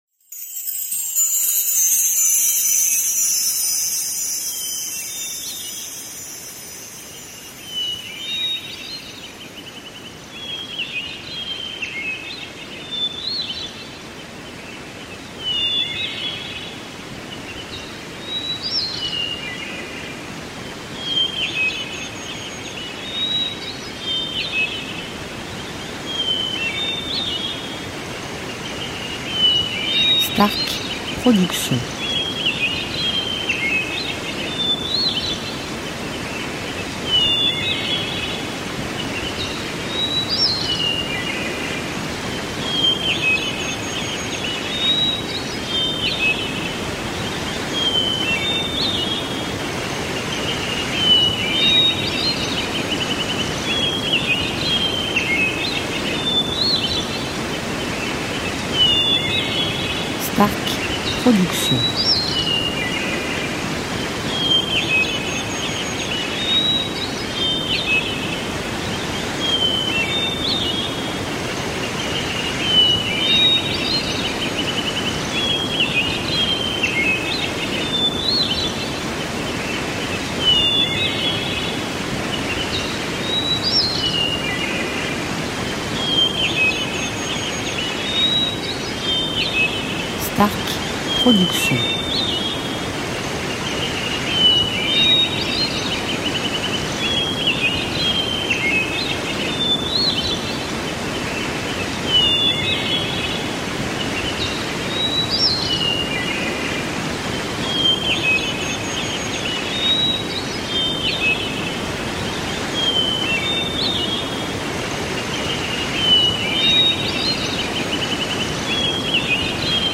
style Sons de la Nature durée 1 heure